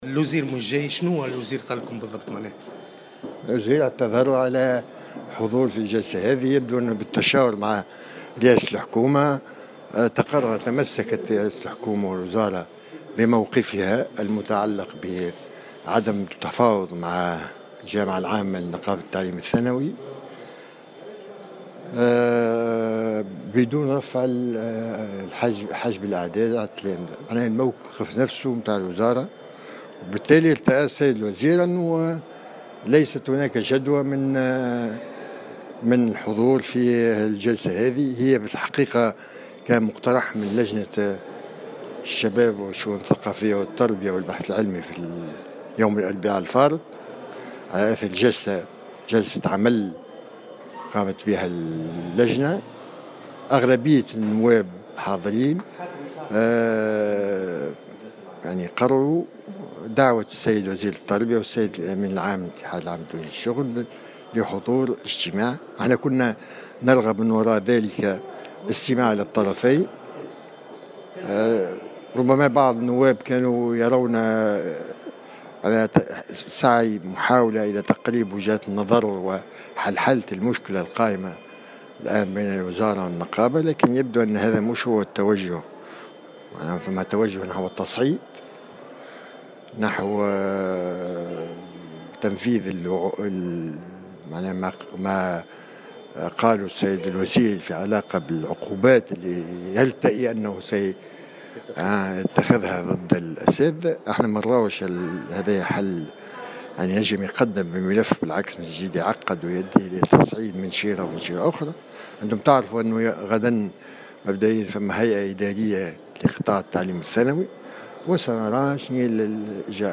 وأوضح في تصريح لمراسل "الجوهرة أف أم"،